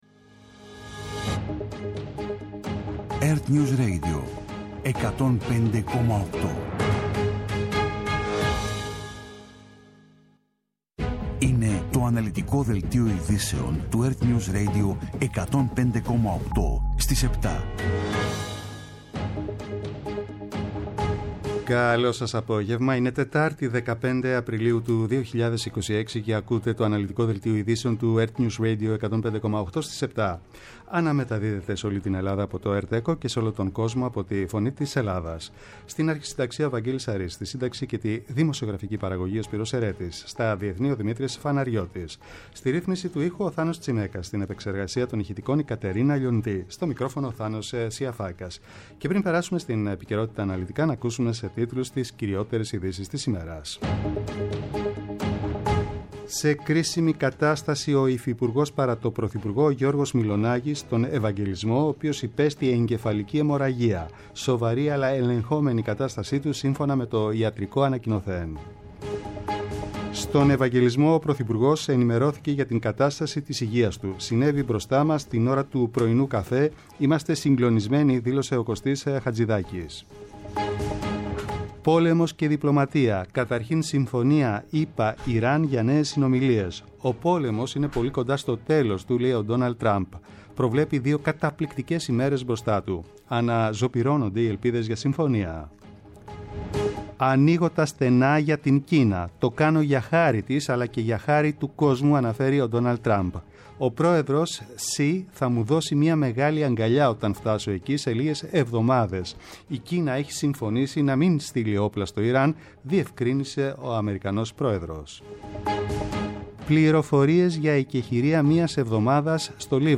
Το αναλυτικό ενημερωτικό μαγκαζίνο στις 19:00. Με το μεγαλύτερο δίκτυο ανταποκριτών σε όλη τη χώρα, αναλυτικά ρεπορτάζ και συνεντεύξεις επικαιρότητας.